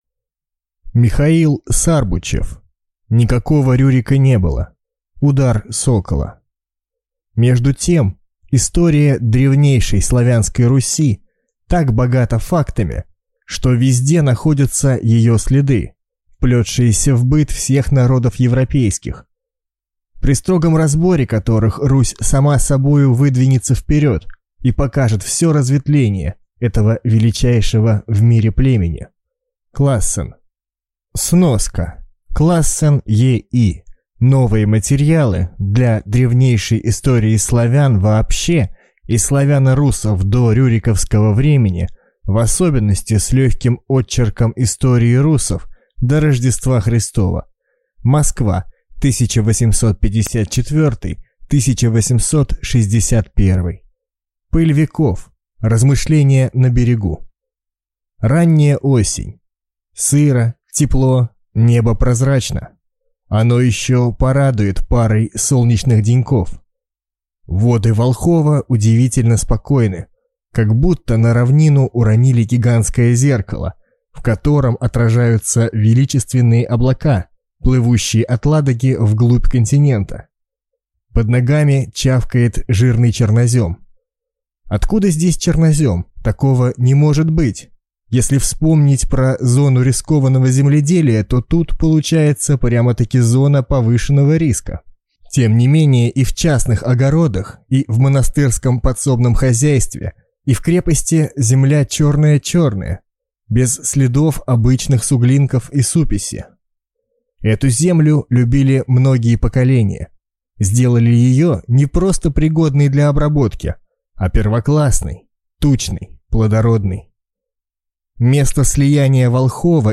Аудиокнига Никакого Рюрика не было?! Удар Сокола | Библиотека аудиокниг